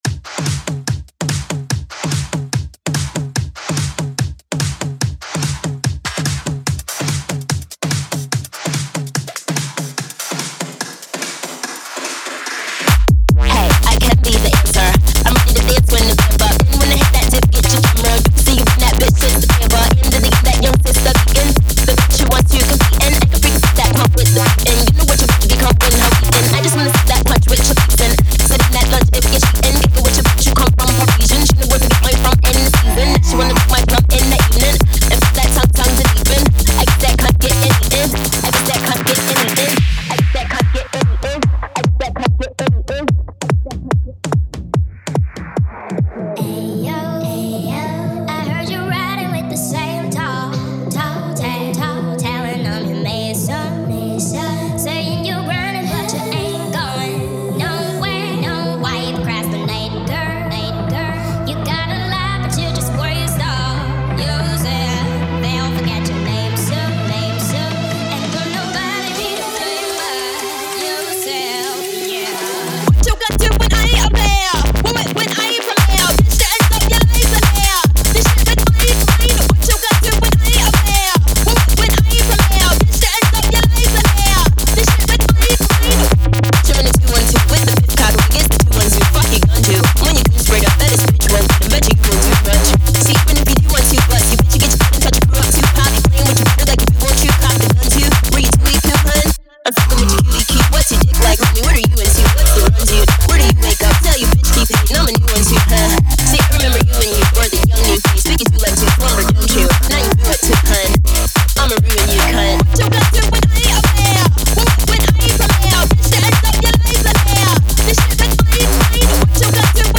bootleg
fidget house # bassline
electro house